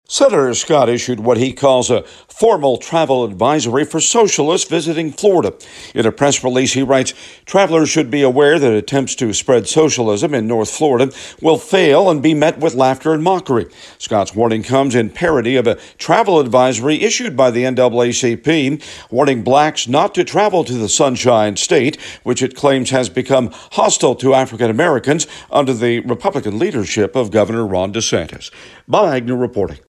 Reporting from Capitol Hill